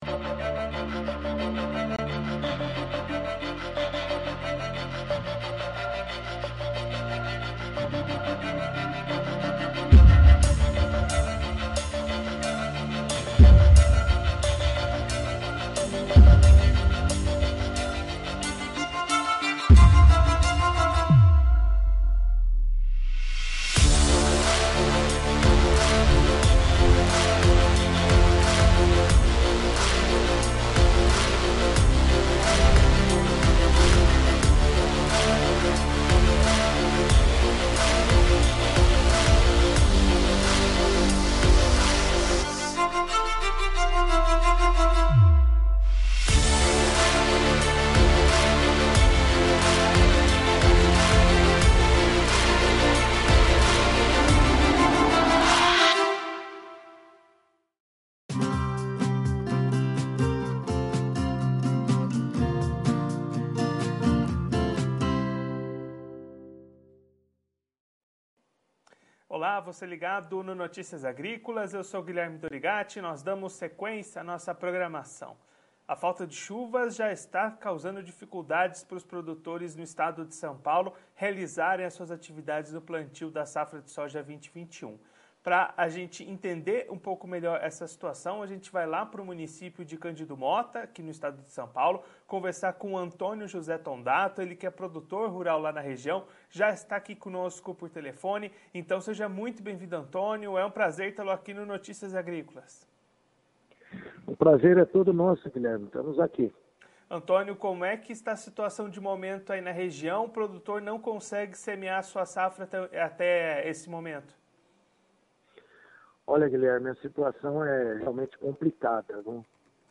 Confira a entrevista completa com o produtor rural de Cândido Mota/SP no vídeo.